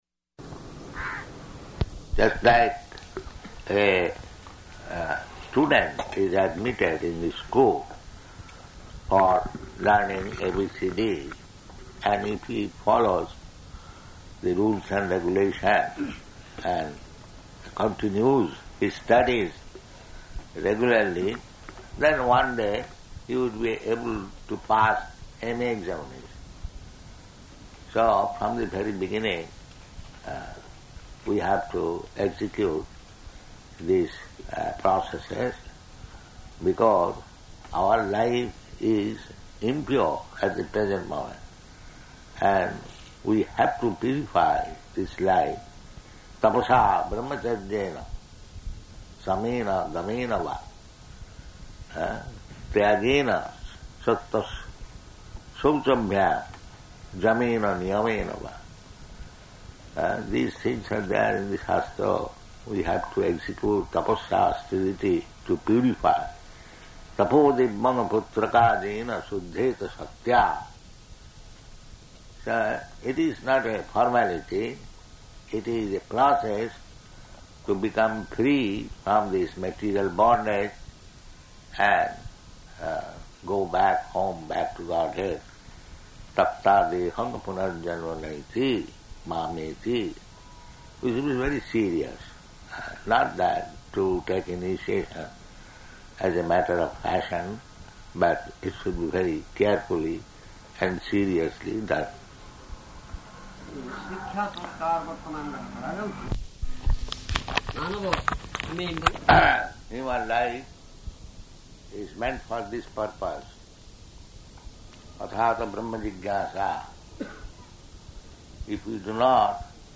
Lecture
Location: Bhavanesvara